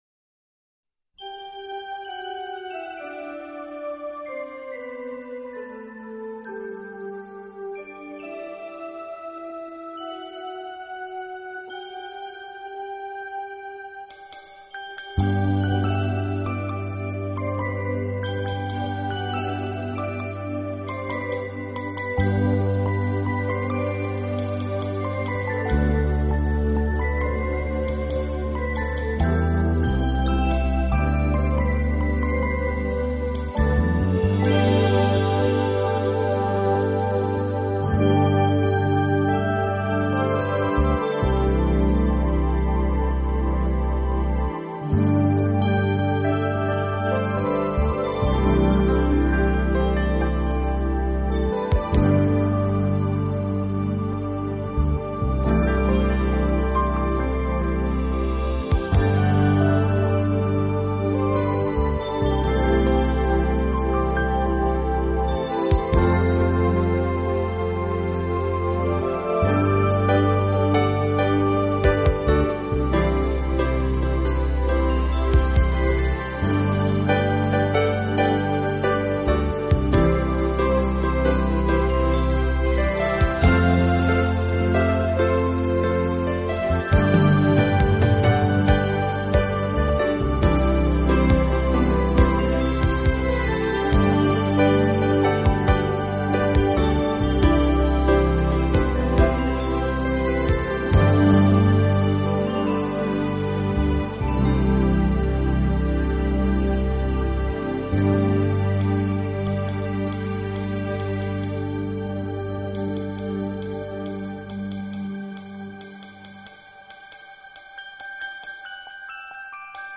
由吉他、鋼琴、電子合成器、大提琴、笛等樂器改編演奏後，除保留原有 的旋律美之外，更增添一份溫暖的透明感。